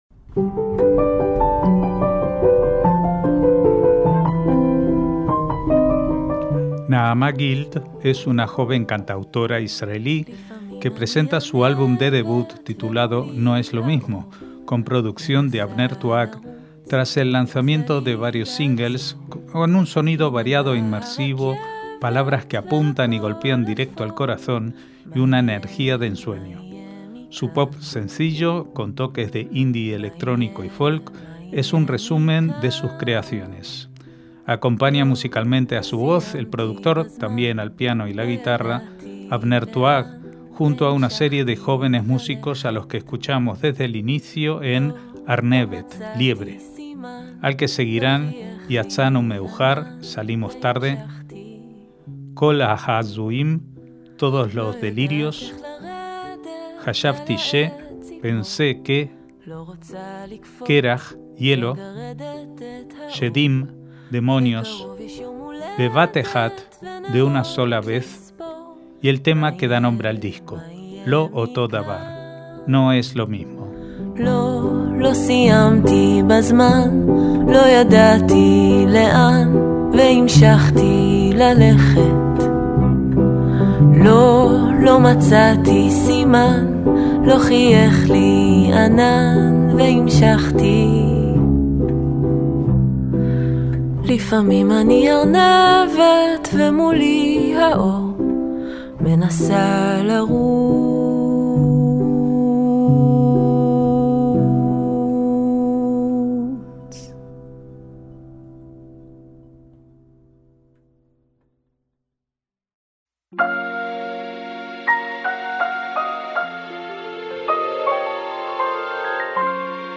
MÚSICA ISRAELÍ
con un sonido variado e inmersivo
piano y la guitarra